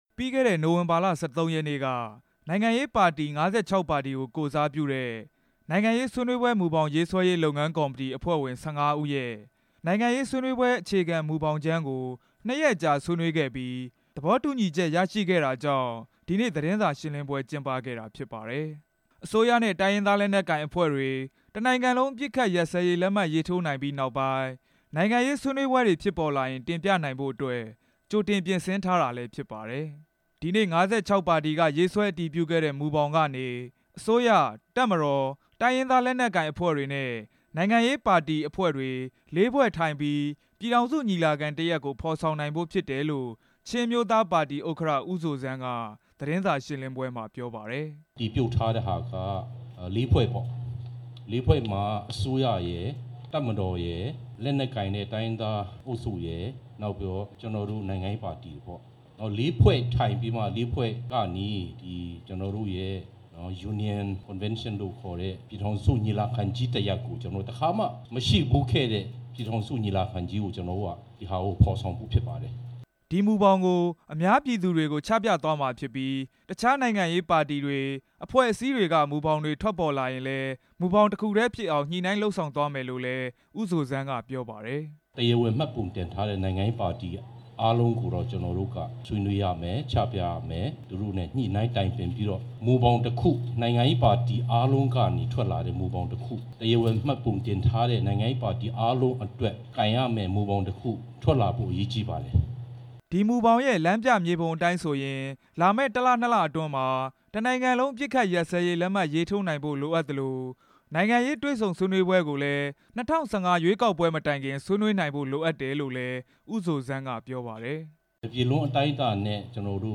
ရန်ကုန်မြို့ ပန်ဒါဟိုတယ်မှာကျင်းပတဲ့ သတင်းစာ ရှင်းလင်းပွဲမှာ နိုင်ငံရေးပါတီ ၅၆ ပါတီက ကိုယ်စားလှယ်တွေ တက်ရောက်ခဲ့ပြီး ရှင်းပြခဲ့ တာဖြစ်ပါတယ်။